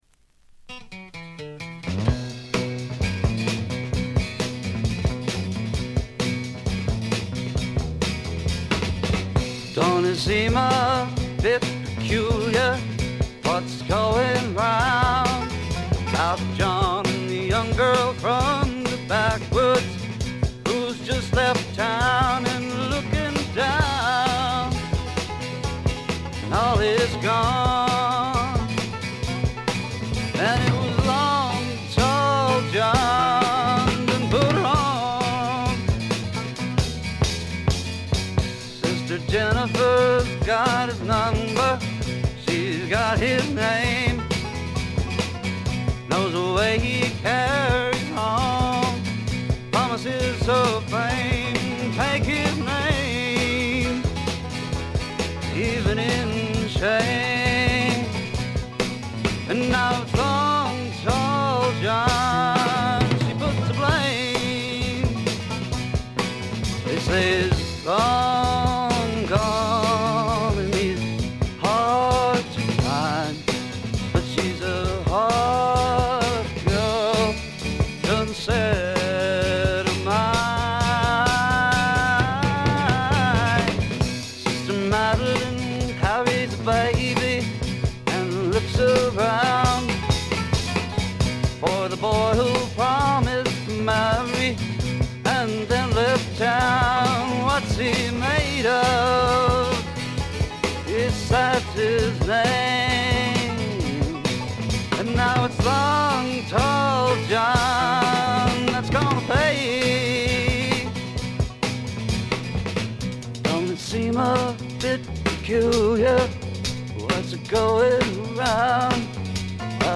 ところどころでチリプチ。
すべて自作曲で独特のヴォーカルもしっかりとした存在感があります。
試聴曲は現品からの取り込み音源です。